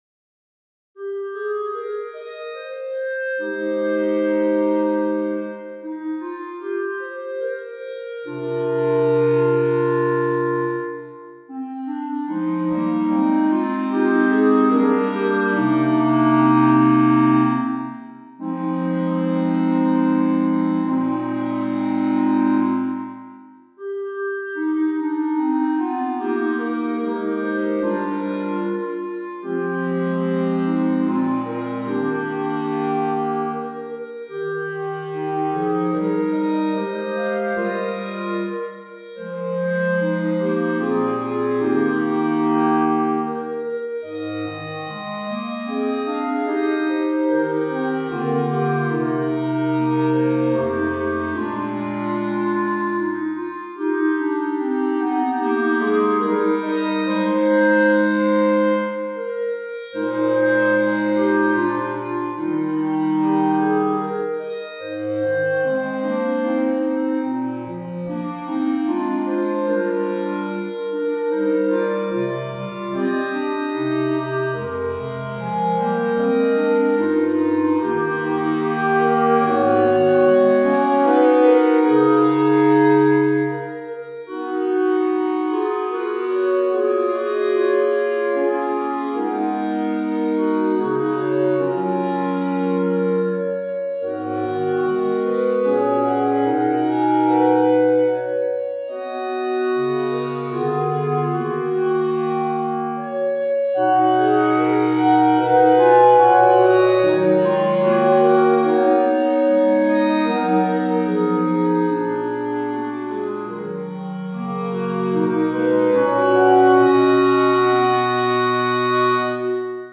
B♭ Clarinet 1 B♭ Clarinet 2 B♭ Clarinet 3 Bass Clarinet
单簧管四重奏
童谣
在看似简单的编排中，巧妙地运用了时尚的和弦，使得乐曲所描绘的梦幻般的景象得以极大延展。